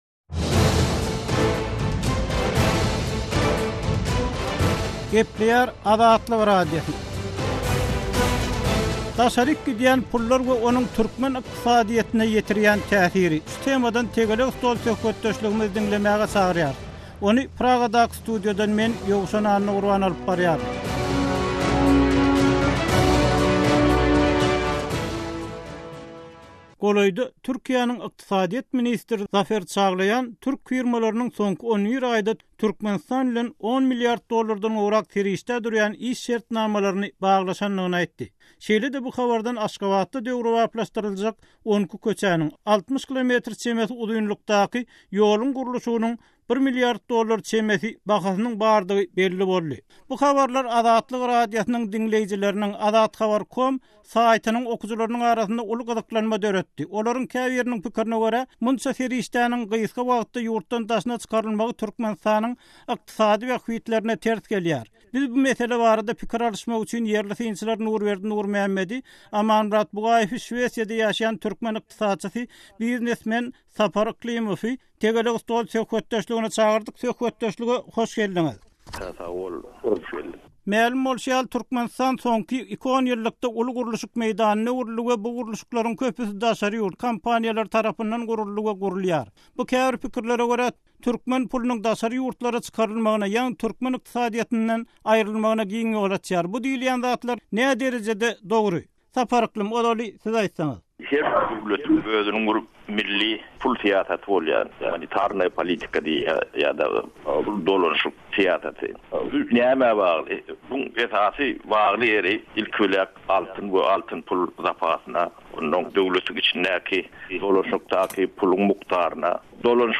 Tegelek stol: Gurluşyga harçlanýan pullar we türkmen ykdysadyýeti